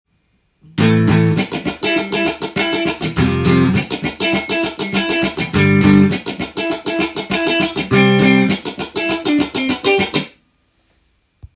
パワーコードとカッティング練習tab譜
譜面通り弾いてみました
ロックで少しファンキーな雰囲気で弾くことが出来ます。
小節の頭に必ずパワーコードを入れて、その後　１６分音符の
でブラッシングしながら２弦の５フレットをカッティングしていく